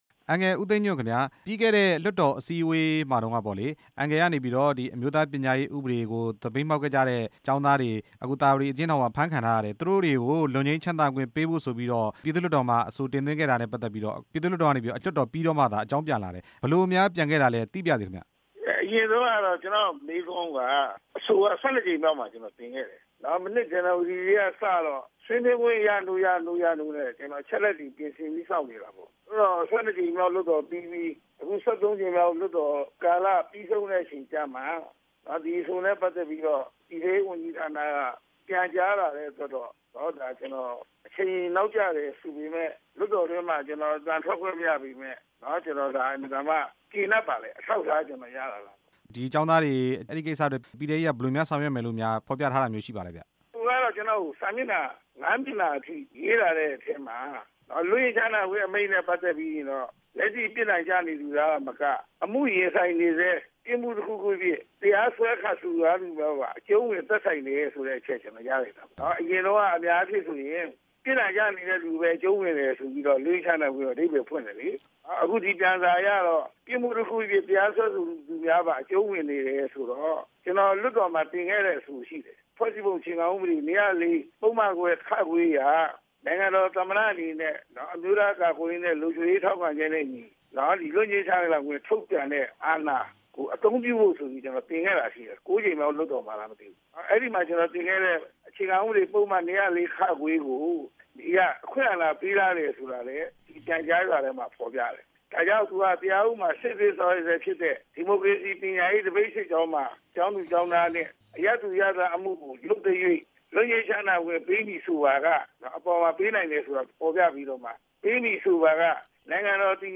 ဆက်သွယ်မေးမြန်းထားတာ